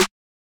kits/OZ/Snares/Snare.wav at main
Snare.wav